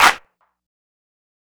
• Clap Single Hit F Key 04.wav
Royality free hand clap - kick tuned to the F note. Loudest frequency: 2160Hz
clap-single-hit-f-key-04-r2E.wav